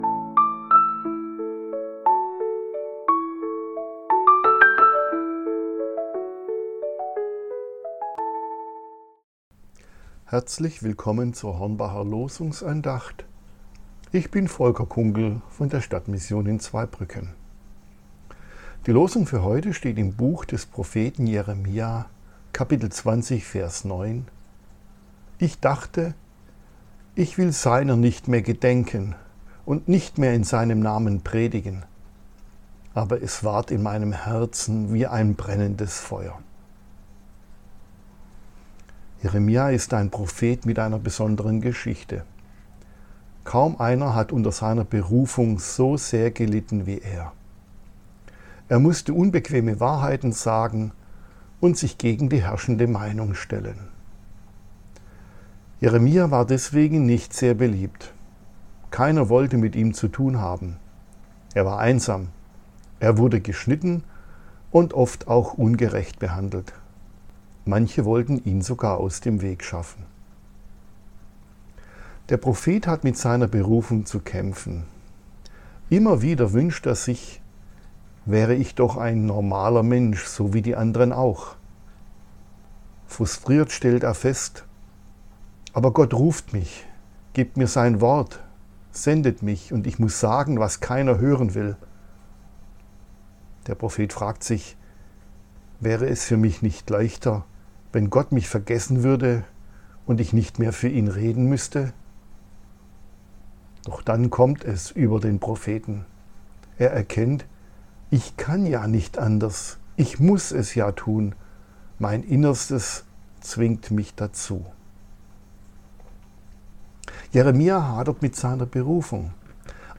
Losungsandacht für Mittwoch, 17.09.2025